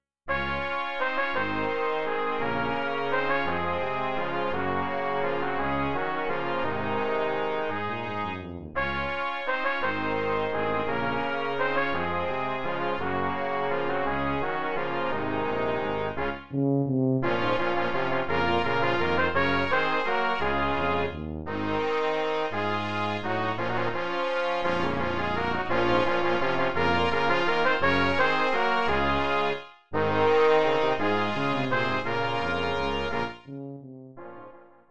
für Blechbläser – Quintett…
Brassquintett